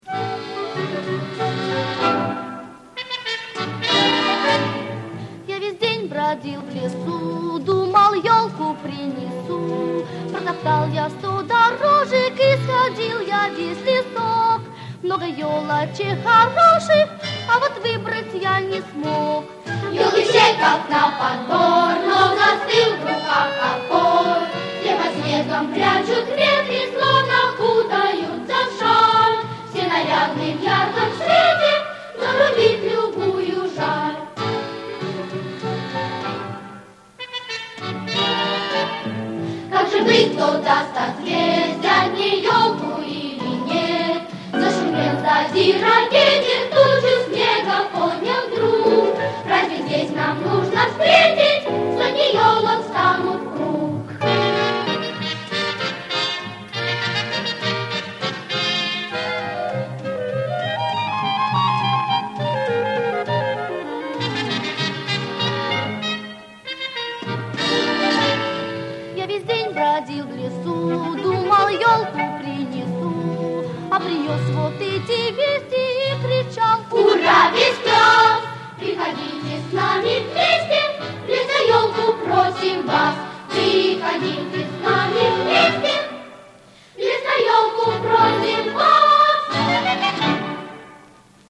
Оптимистическая новогодняя песня